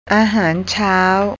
breakfast ah-haahn chao อาหารเช้า